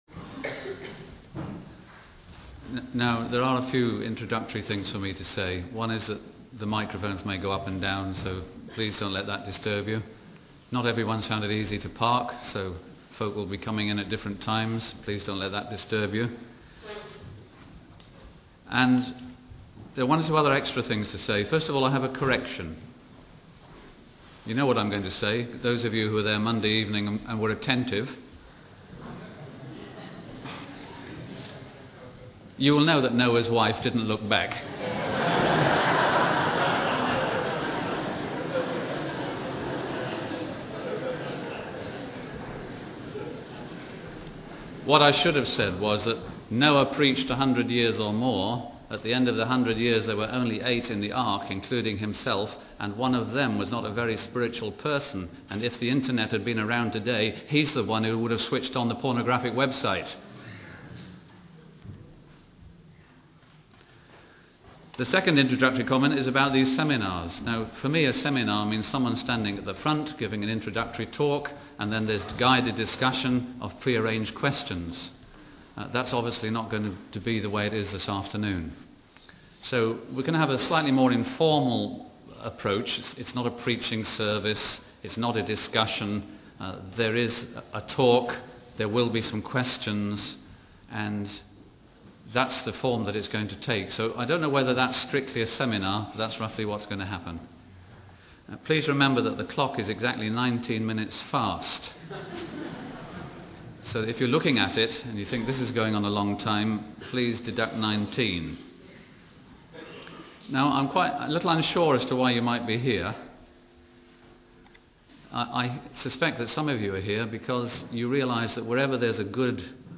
In this sermon, the speaker begins by referencing the story of Noah and the ark, highlighting that out of the eight people in the ark, one was not a very spiritual person. The speaker then discusses the format of the seminar, explaining that it will be more informal with a talk and some questions.